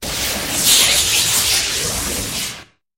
Звук стремительного накопления электрической энергии